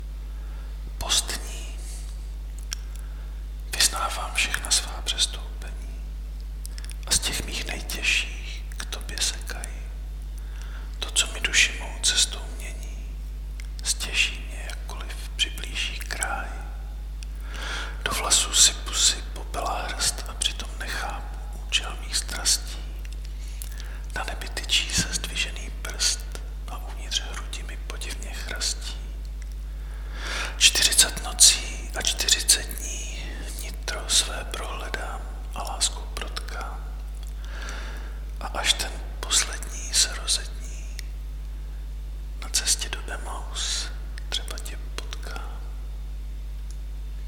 Básně » Ostatní
ten šepot tomu dal posvátnost
Dal jsem těm slovům prostor, aby vyzněla víc jako modlitba někde v zadním rohu chrámu.